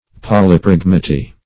Search Result for " polypragmaty" : The Collaborative International Dictionary of English v.0.48: Polypragmaty \Pol`y*prag"ma*ty\, n. [Poly- + Gr.
polypragmaty.mp3